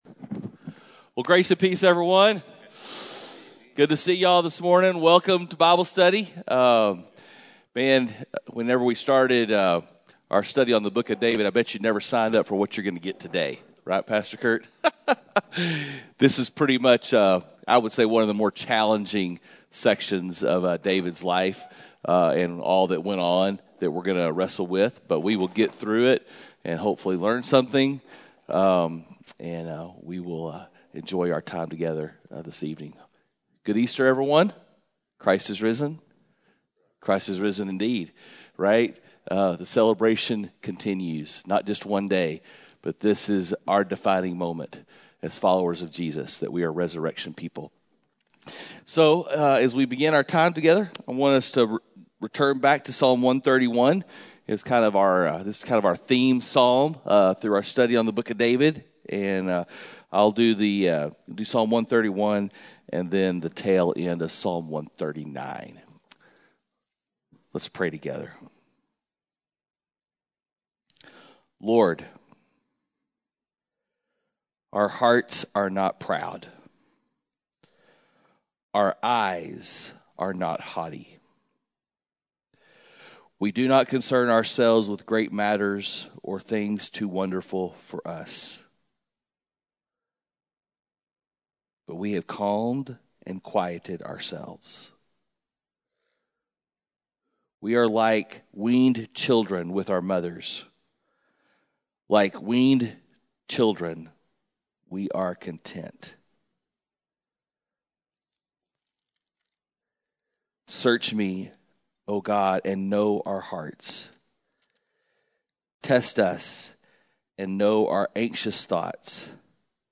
Men’s Breakfast Bible Study 4/6/21